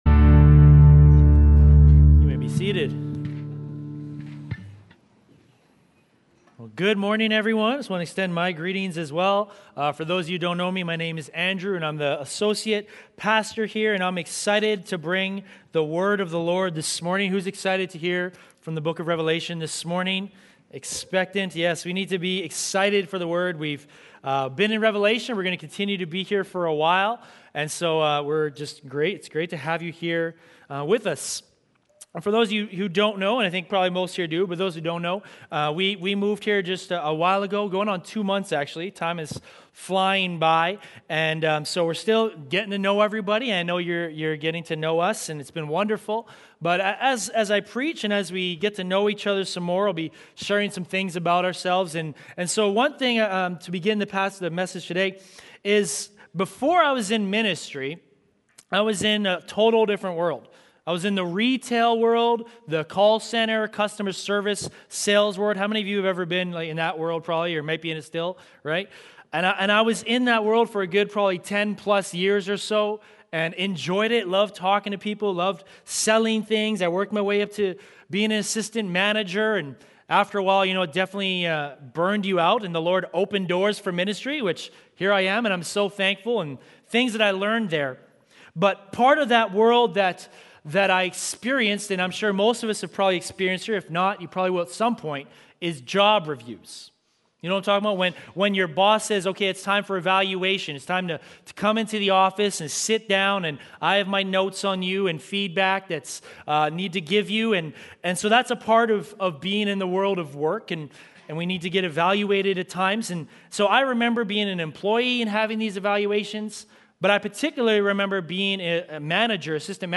Sermons | Sturgeon Alliance Church